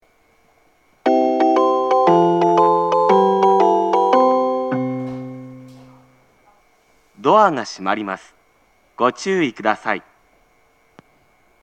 発車メロディー
一度扱えばフルコーラス鳴ります。
混線することが稀にあります。